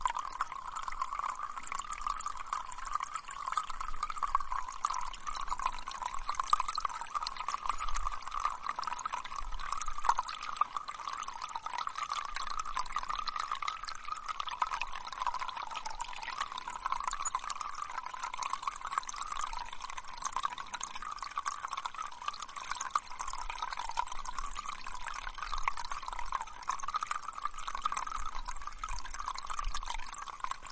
描述：这是在降雨后在山区火灾道路上的小溪流的现场记录。
Tag: 山消防道路 现场录音 溪流 之后降雨